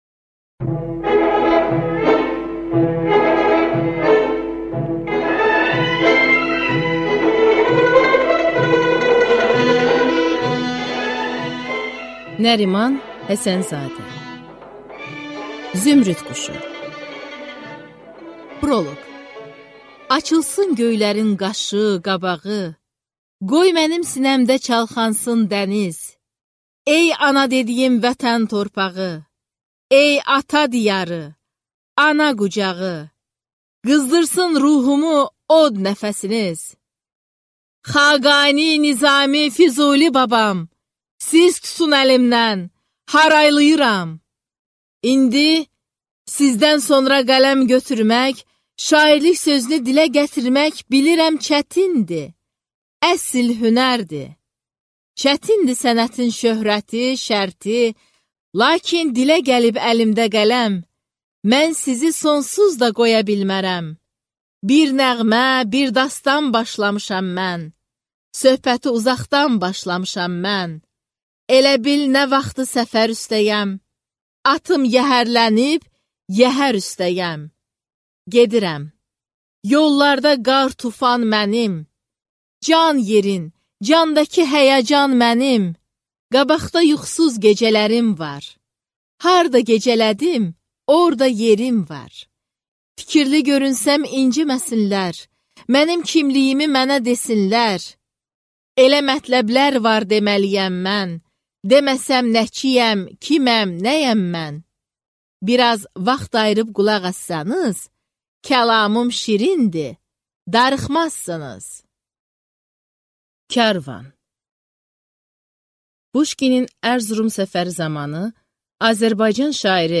Аудиокнига Zümrüd quşu | Библиотека аудиокниг